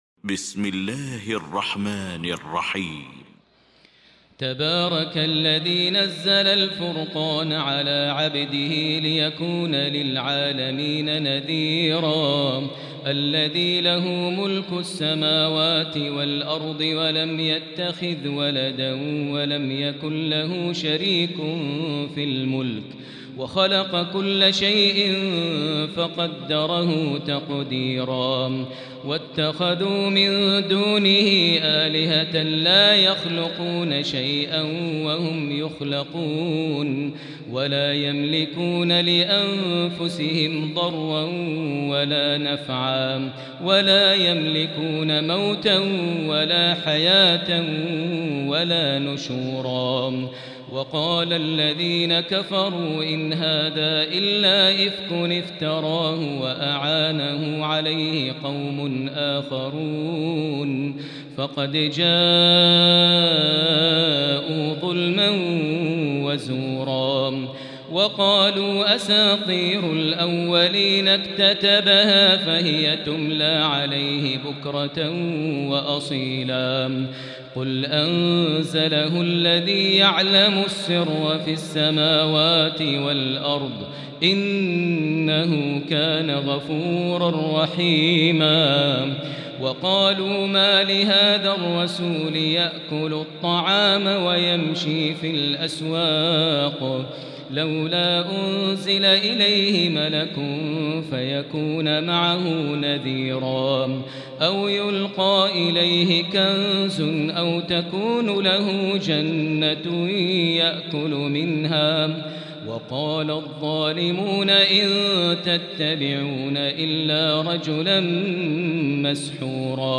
المكان: المسجد الحرام الشيخ: فضيلة الشيخ ماهر المعيقلي فضيلة الشيخ ماهر المعيقلي الفرقان